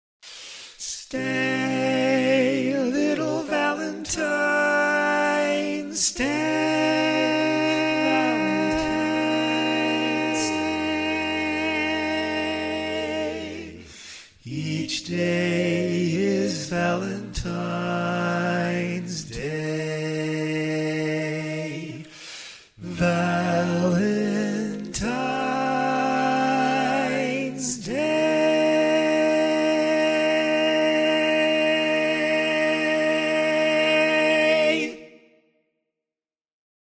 Key written in: B Minor
Type: Barbershop